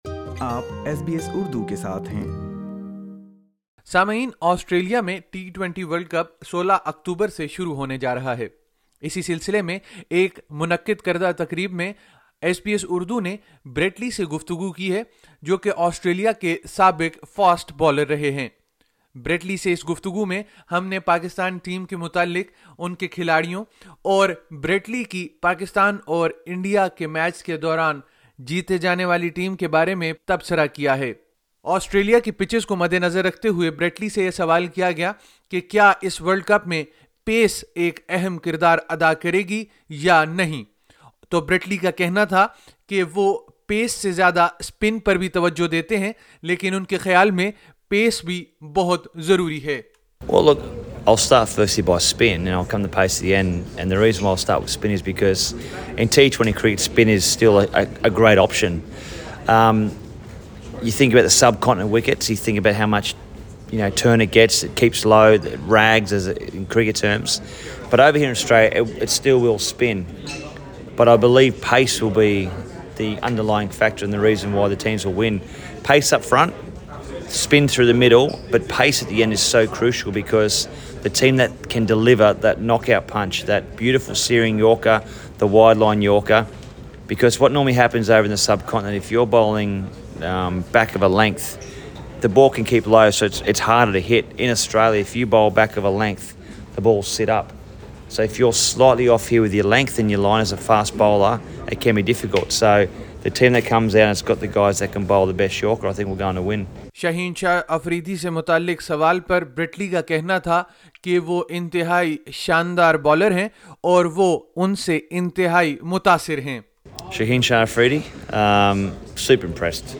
In an interview with SBS Urdu, former Australian fast bowler Brett Lee said that the India-Pakistan match in MCG will be a memorable match.